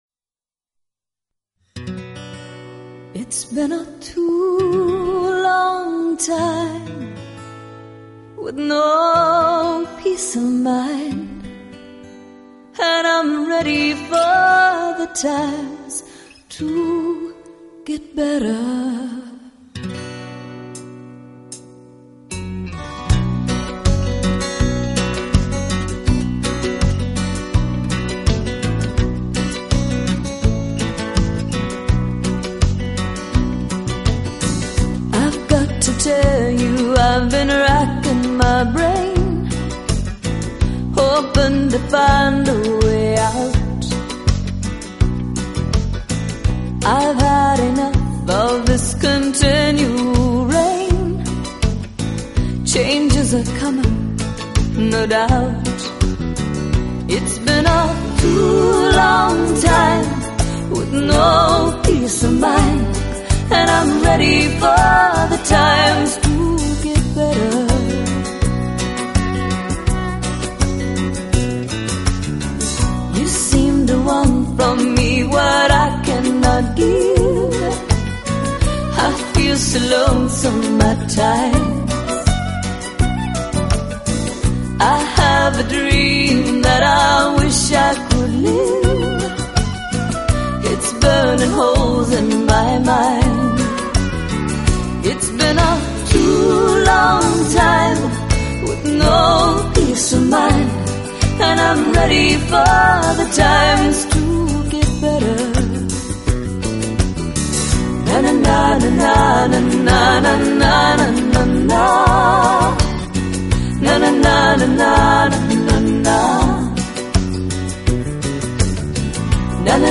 它们由柔美的旋律与不愠不火的细致唱腔构成，喜悦与悲伤的情愫相互 交替，象呼吸一样让人感到舒心自然。
木吉他的朴素和弦，民谣歌手的真情 演绎，帮我们寻回人类的童真，生活的安宁。乡村宁静雅致，民谣清新质朴。